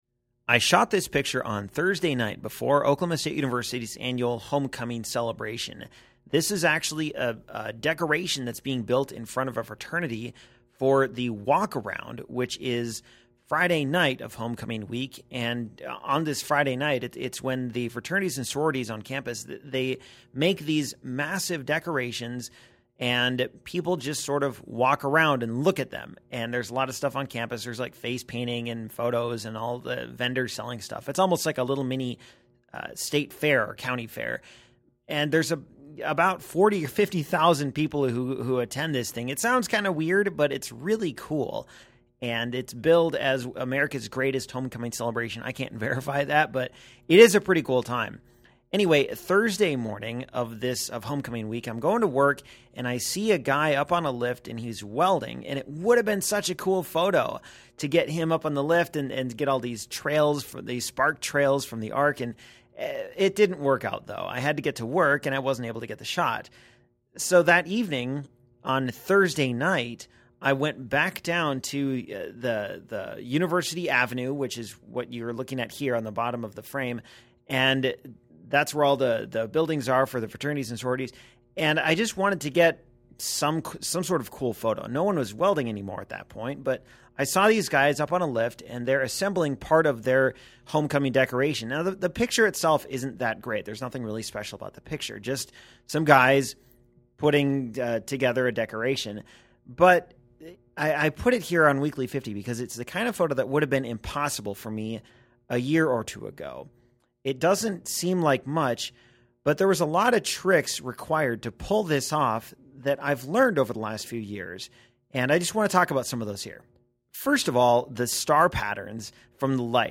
I recorded the commentary using GarageBand on my Mac, and then uploaded it to the website.